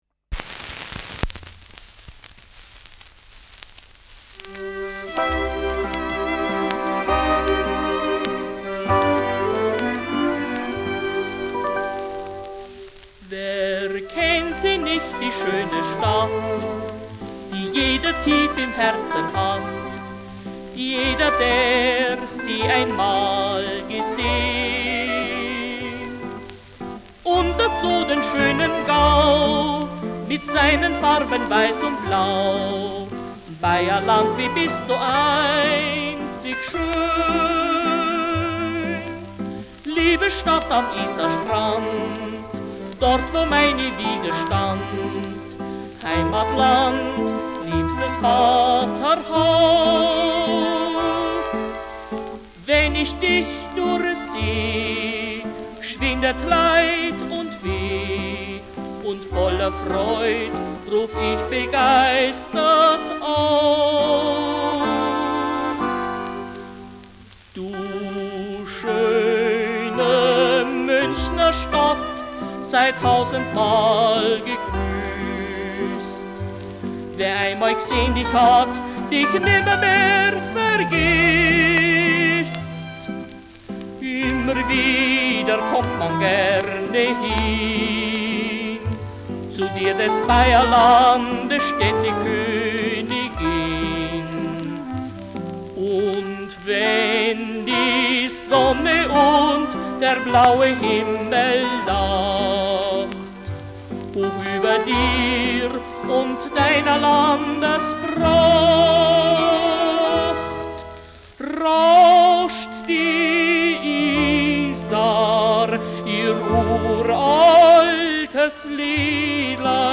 Schellackplatte
Nadelgeräusch : Gelegentlich leichtes Knacken : Abgespielt